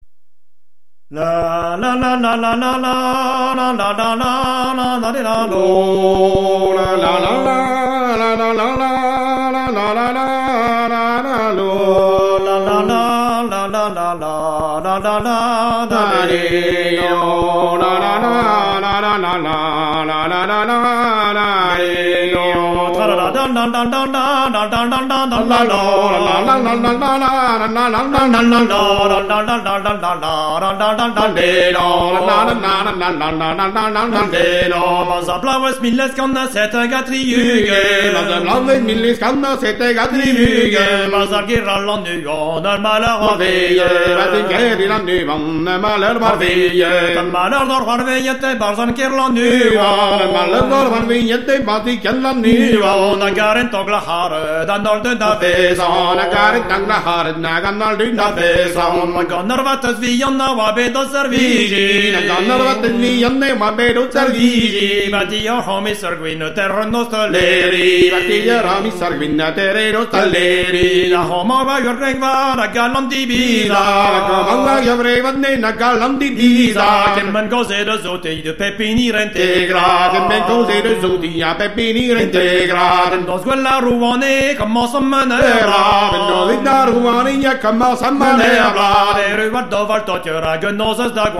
"Kan ha diskan"
L'accompagnement musical peut être instrumental, mais le plus souvent vocal et consiste alors en un chant alterné soit entre deux solistes, selon le procédé dit "kan ha diskan", soit entre un soliste et un choeur.